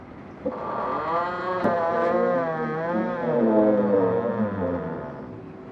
吱吱声 " 吱吱声2 PS+4
描述：来自一扇门的Creak罗德NTG2麦克风音高达4个半音
标签： 吱吱嘎嘎作响 铰链 叽叽嘎嘎 吱吱
声道立体声